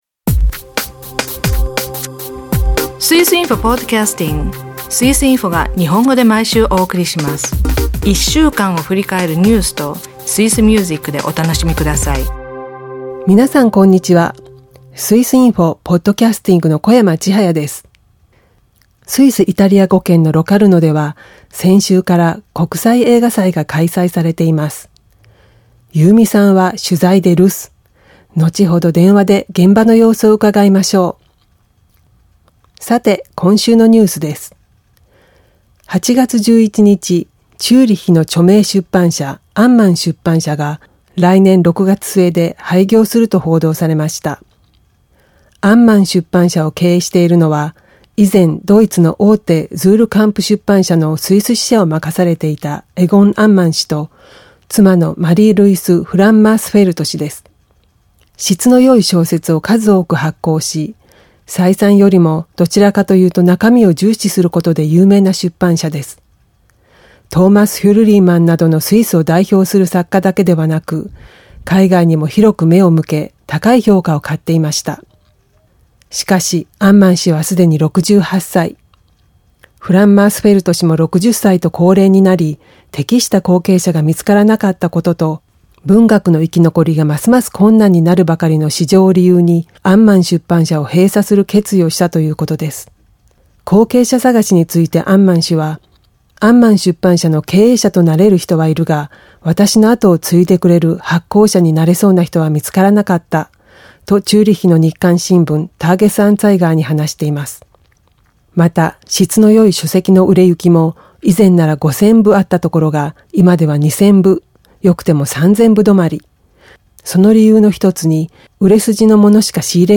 日本色いっぱいのロカルノ国際映画祭で小林政広監督にインタビュー。新型インフルエンザに病院で感染するケースが報告されています。